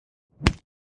Fight Sounds » Kung Fu punch 5
描述：A series of Kung Fu style punch noises I created for fun using sounds I had lying about. Low and fast.
标签： comical whack suckerpunch action crack fist kung smack fight fighting punch whoosh pow puch kungfu fu hit sucker funny impact
声道立体声